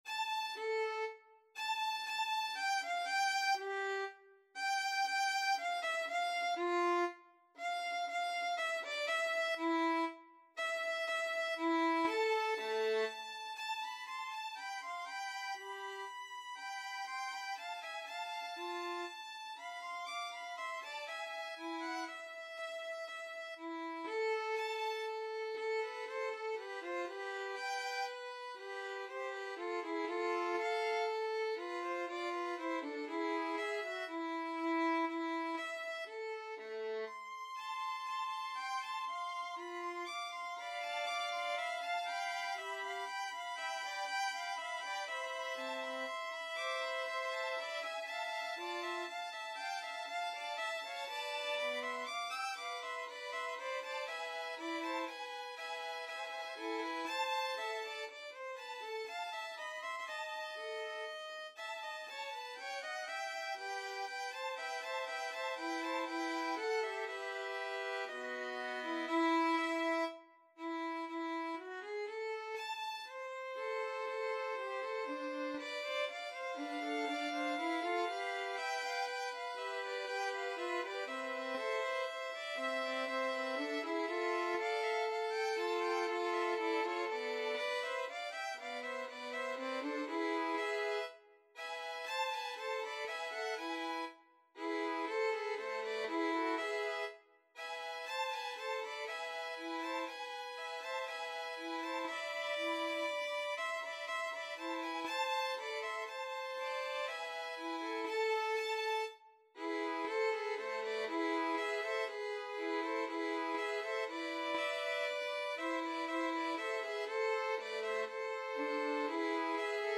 3/4 (View more 3/4 Music)
Violin Trio  (View more Intermediate Violin Trio Music)
Classical (View more Classical Violin Trio Music)